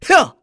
Lusicas-Vox_Attack3_kr.wav